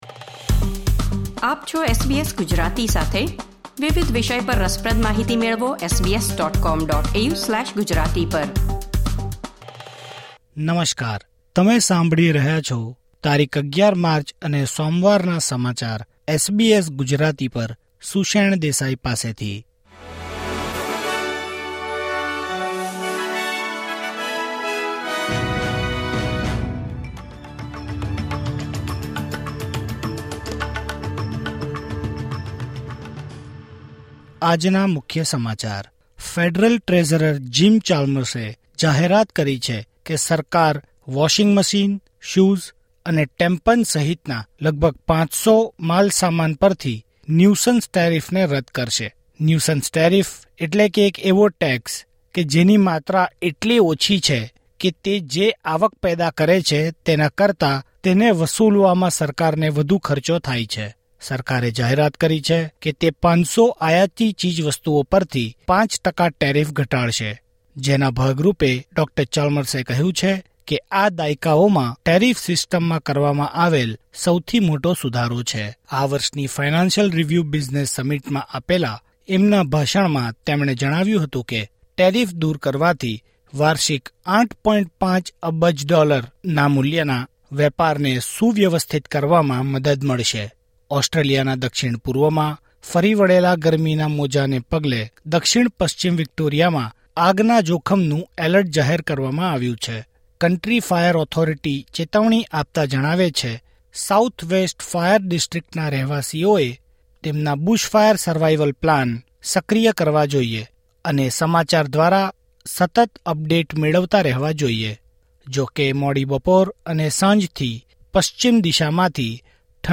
SBS Gujarati News Bulletin 11 March 2024